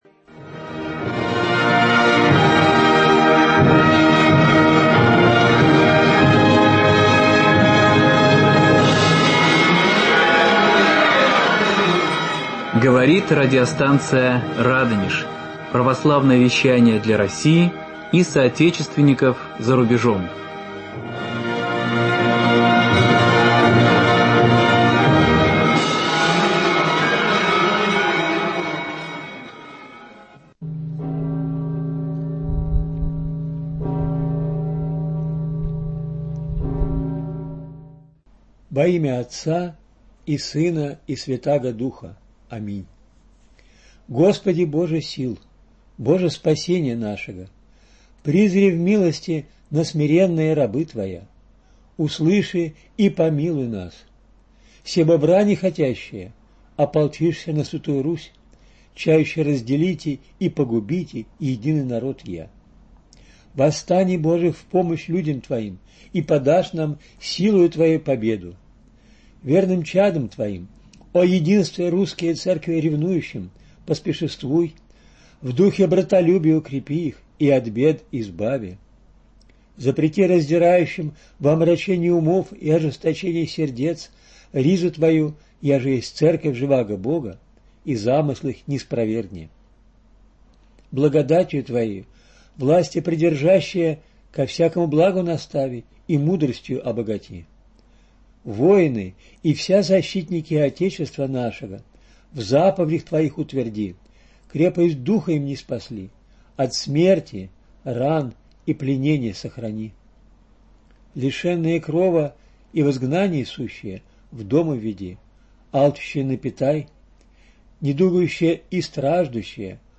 Самовидцы дней минувших. Беседа